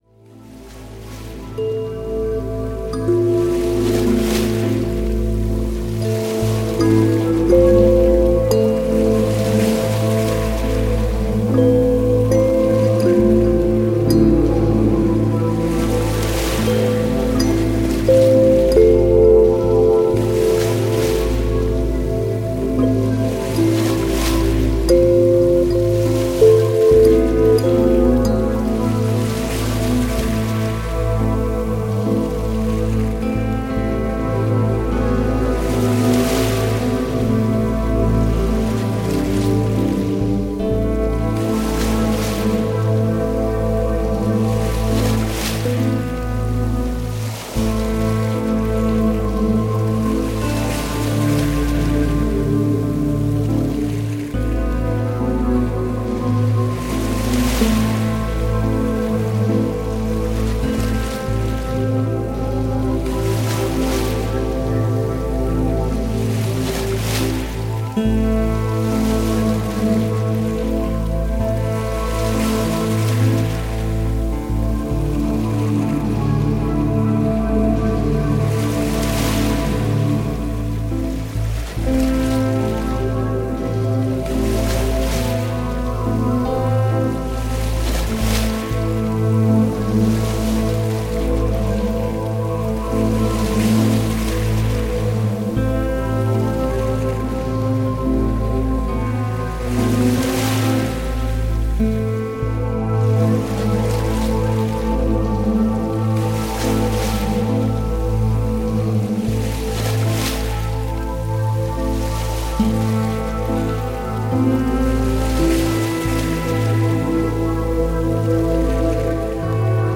Музыка природы